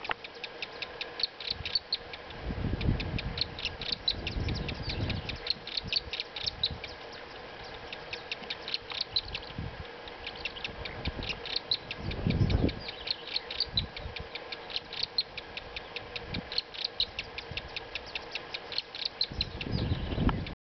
Fulvous-faced Scrub Tyrant (Euscarthmus fulviceps)
Life Stage: Adult
Detailed location: Caral
Condition: Wild
Certainty: Photographed, Recorded vocal